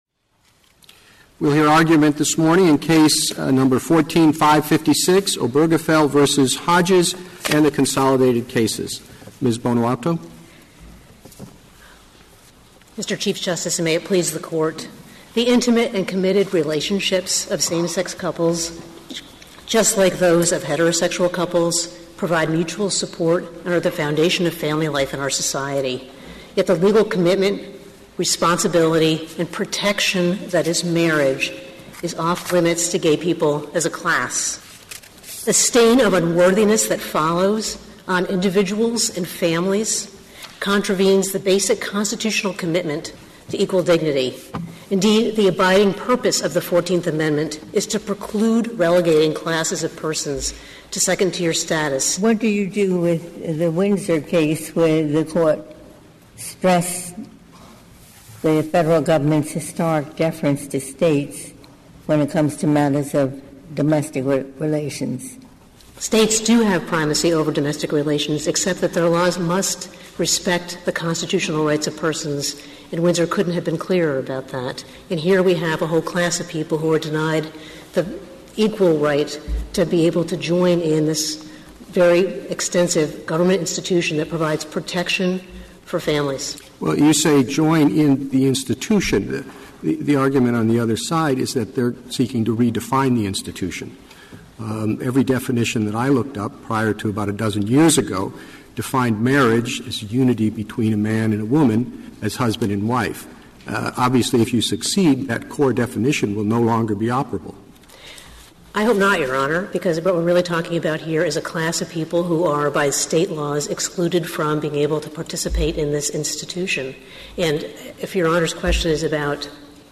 Here is the first question addressed in this morning's Supreme Court hearing on same sex marriage.